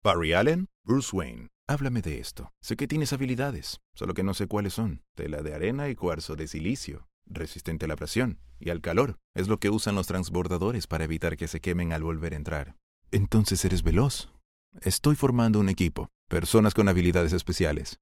male | Universal Latin American | Characters | adult